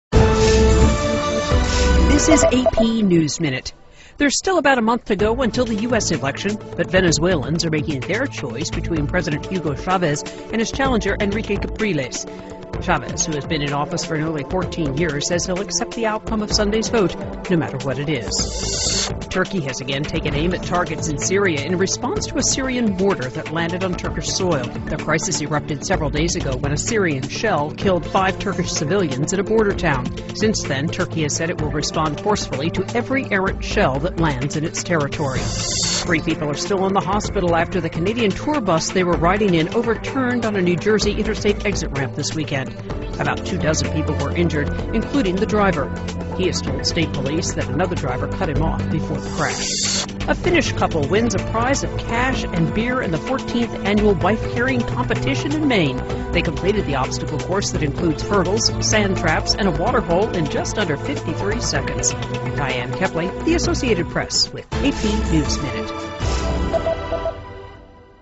在线英语听力室美联社新闻一分钟 AP 2012-10-09的听力文件下载,美联社新闻一分钟2012,英语听力,英语新闻,英语MP3 由美联社编辑的一分钟国际电视新闻，报道每天发生的重大国际事件。电视新闻片长一分钟，一般包括五个小段，简明扼要，语言规范，便于大家快速了解世界大事。